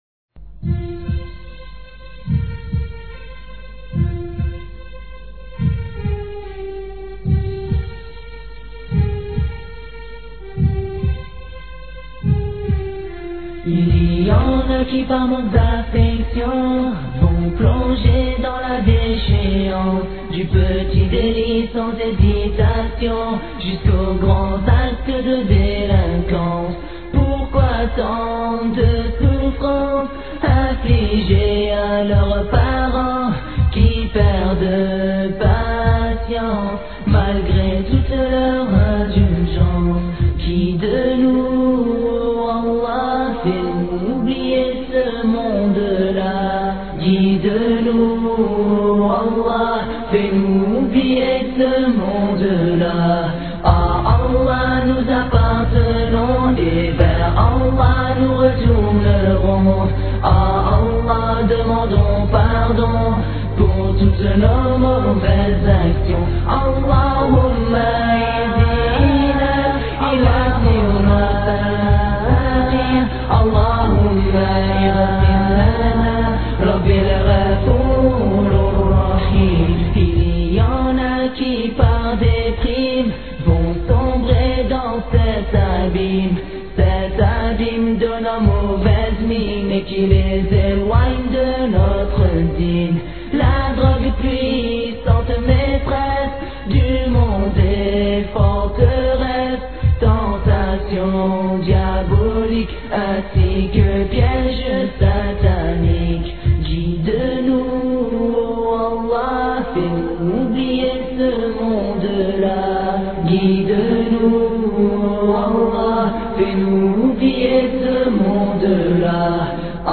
Pardon orateur: quelques jeunes période de temps: 00:00:00